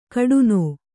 ♪ kaḍunō